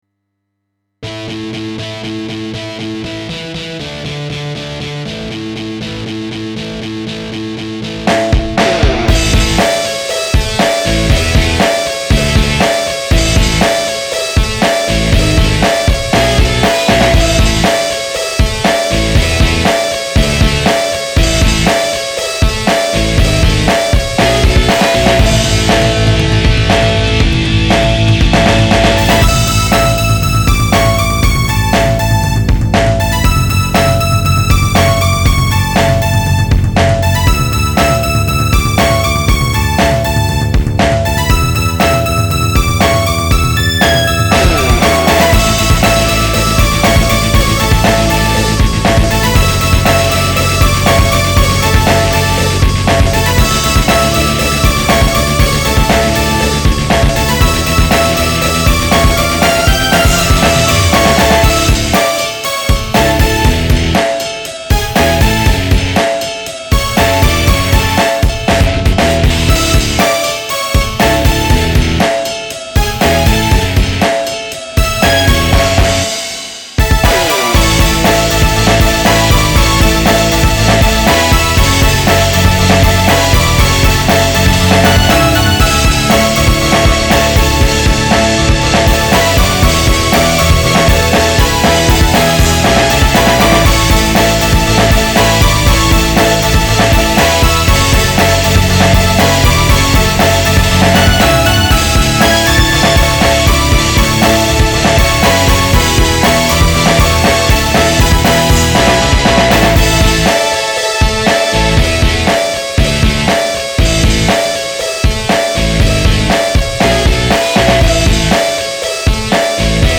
以激烈且充满气势的音乐为中心，尽是个性丰富的素材。